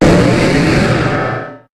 Cri de Méga-Léviator dans Pokémon HOME.
Cri_0130_Méga_HOME.ogg